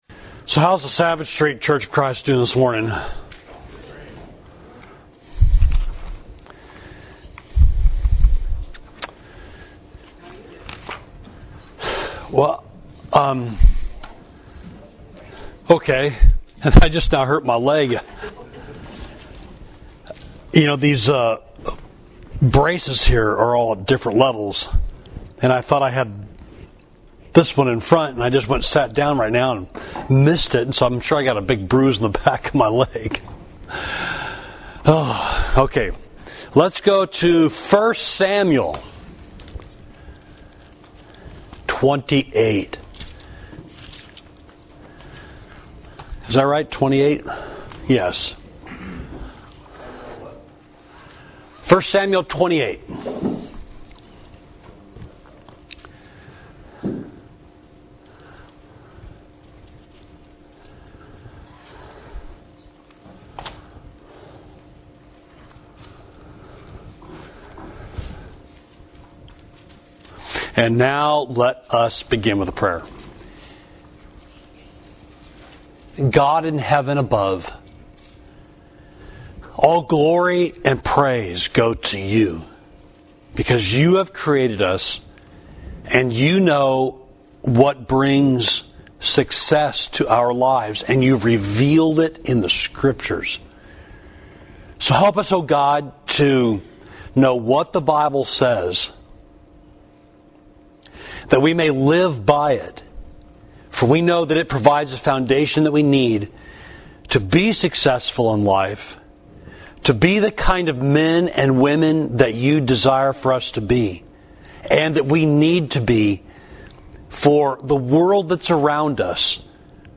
Class: What Does the Bible Say about Talking to the Dead?